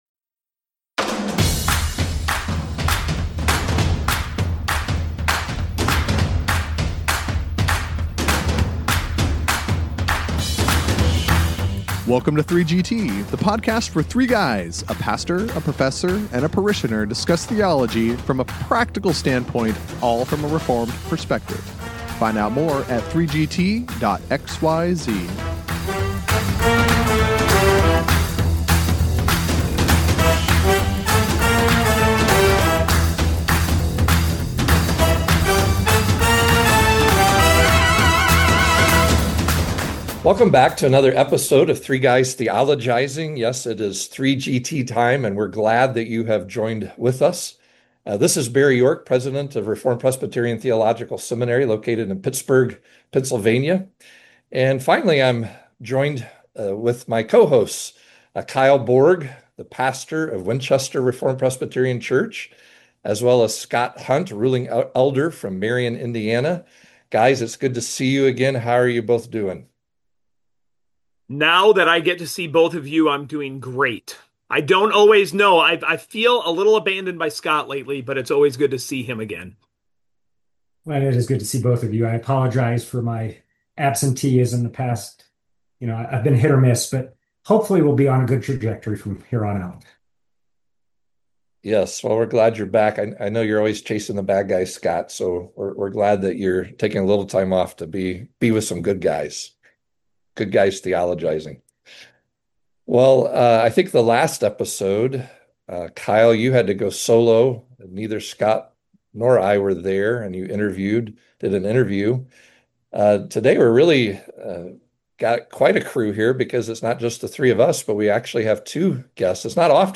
The three guys are back, and they have not one, but two guests on this episode!